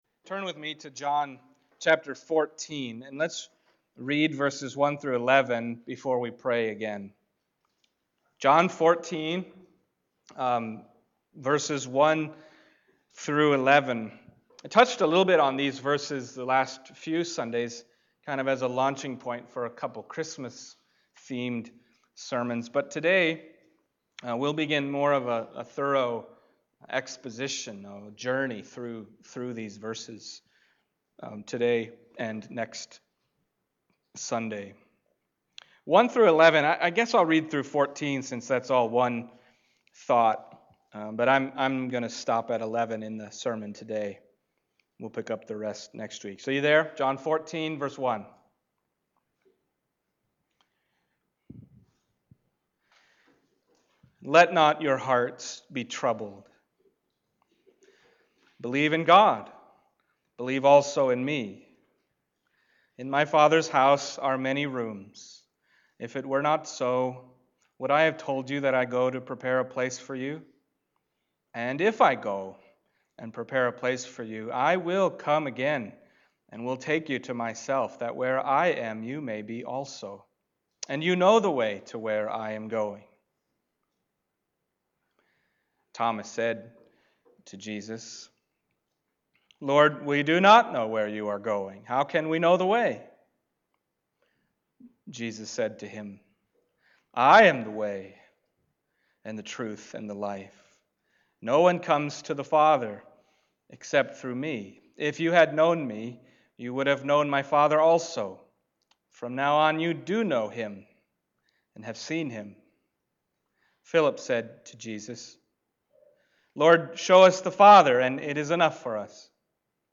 John Passage: John 14:1-11 Service Type: Sunday Morning John 14:1-11 « God With Us